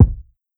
Stomp Kick.wav